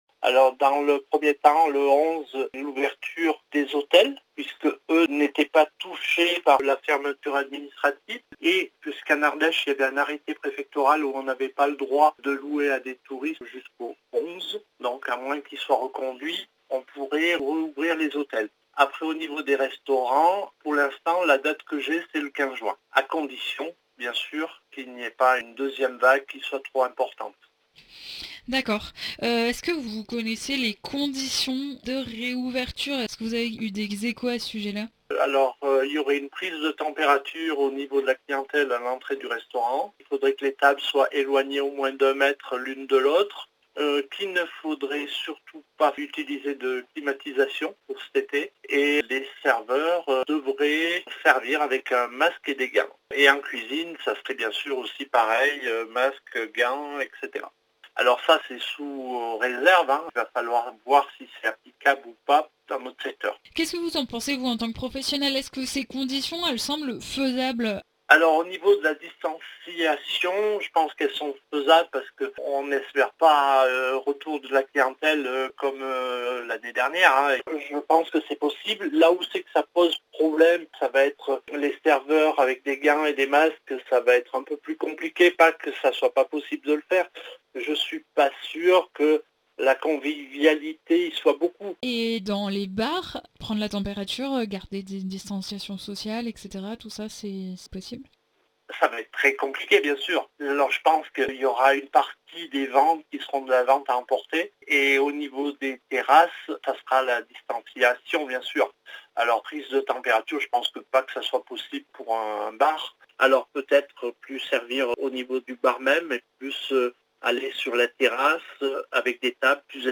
RESTO ITW 1
RESTO-ITW-1.mp3